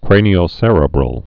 (krānē-ō-sə-rēbrəl, krānē-ō-sĕrə-brəl)